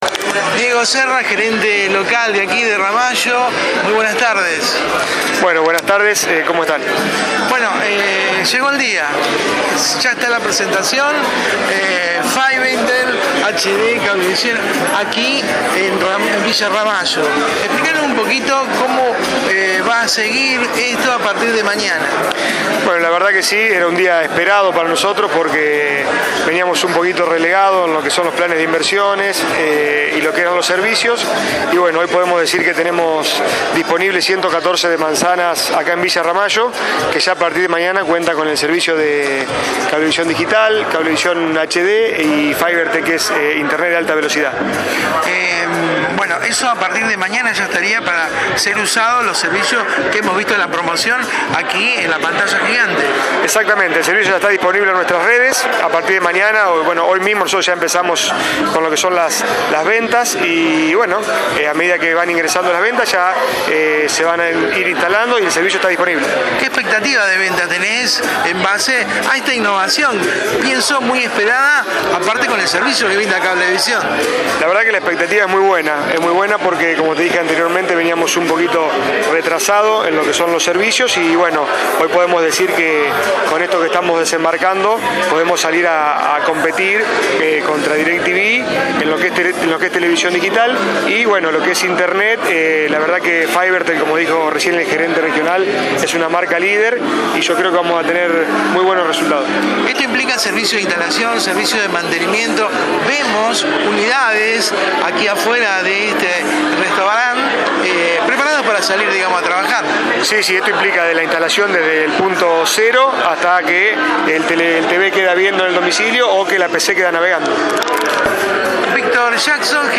La invitación a esta conferencia de prensa fue realizada para todos los medios de prensa locales donde los directivos de Cablevisión expusieron todo lo trascendental del tema para que esto sucediera.